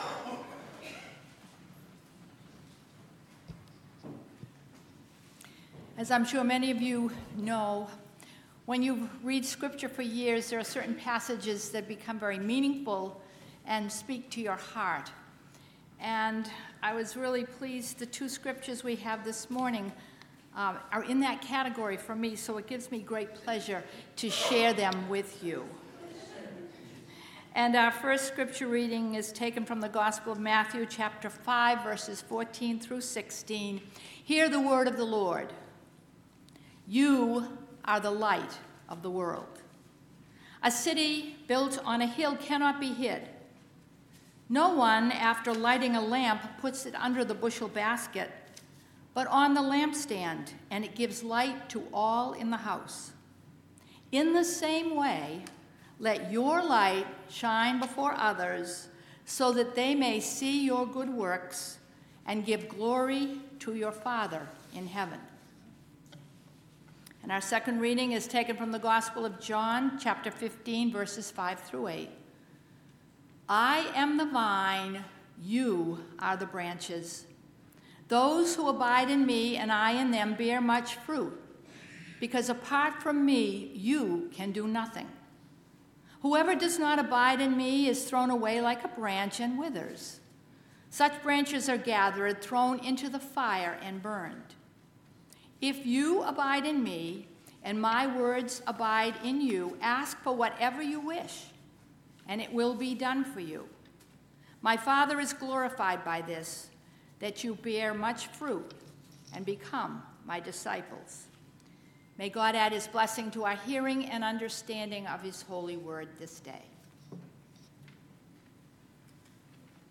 VBS Sunday Service w